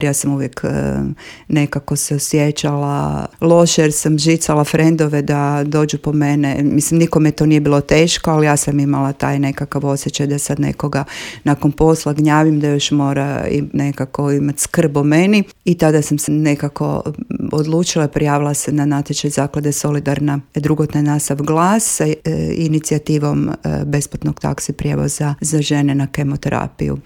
gostovala je u Intervjuu Media servisa u kojem je govorila o svemu što stoji iza ove prestižne nagrade te najpoznatijim projektima udruge